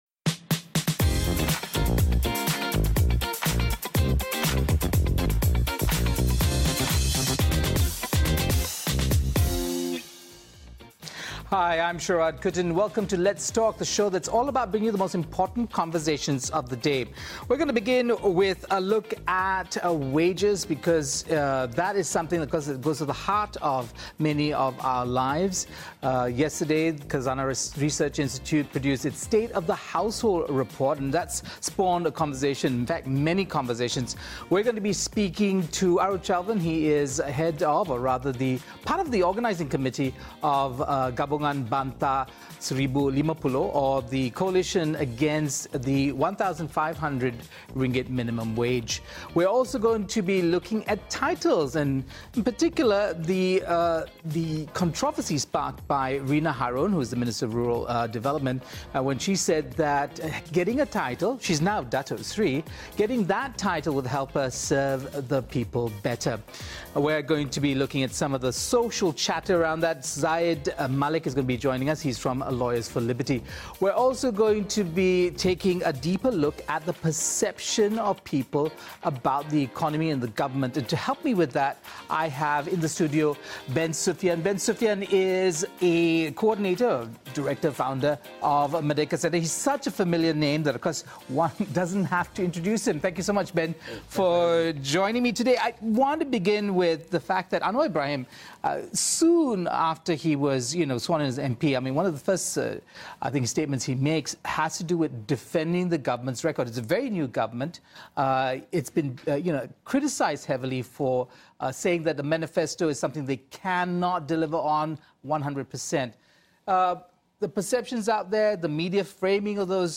Reflecting on the Khazanah Research Institute's Household Report and public opinion polling, have in the studio